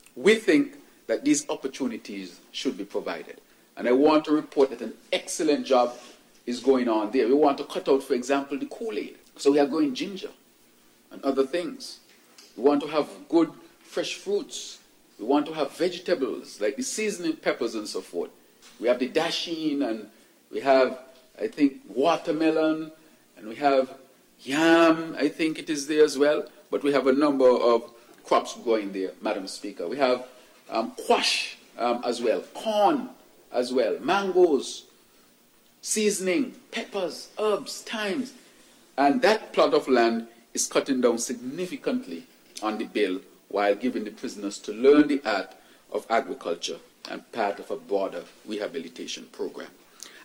2nd reading of the Prison (Amendment) Bill, 2025 heard Thursday in the National Assembly
PM Drew said that he visited the Prison Farm on Wednesday and the purpose of creating such a farm is to produce and grow healthy food: